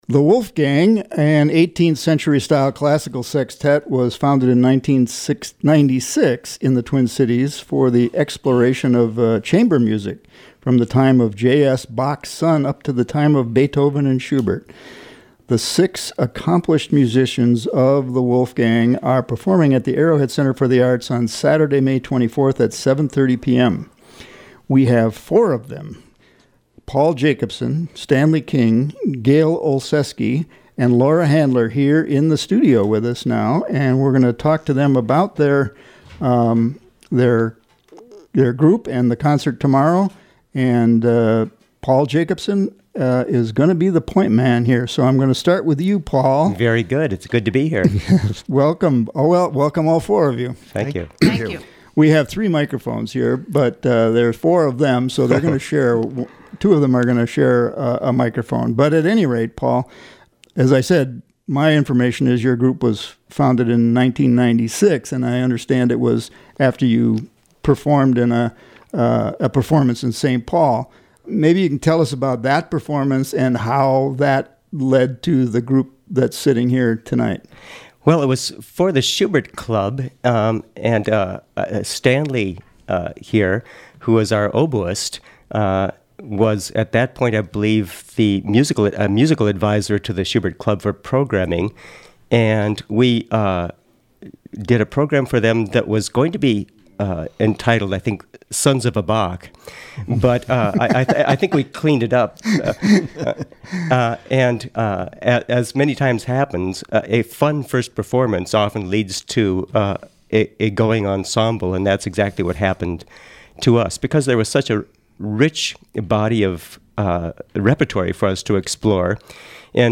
Two cuts from their CD are played in part during the interview.